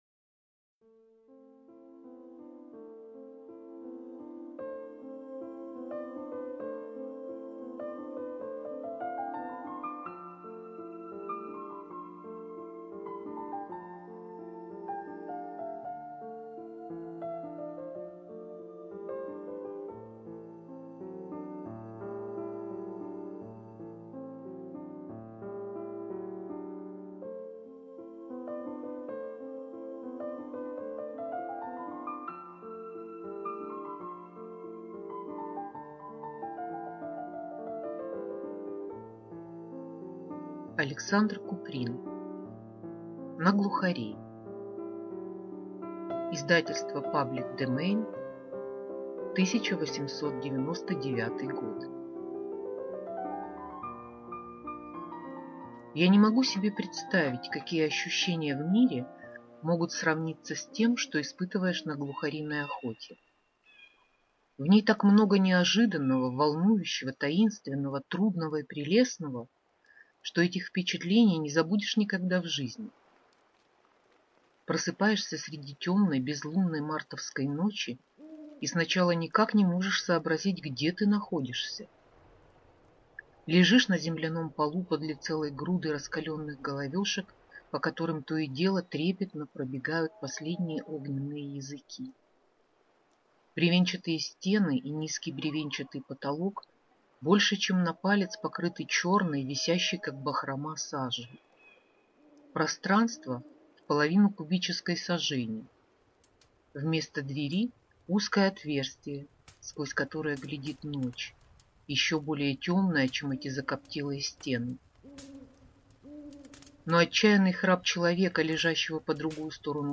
Аудиокнига На глухарей | Библиотека аудиокниг